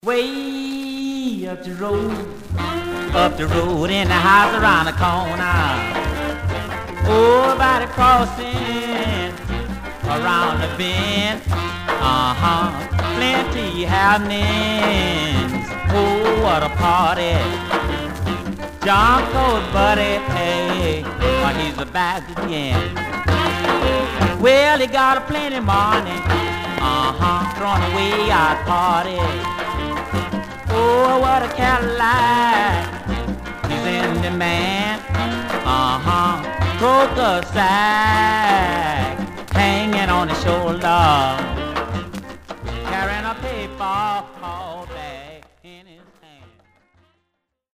Much surface noise/wear Stereo/mono Mono
Rythm and Blues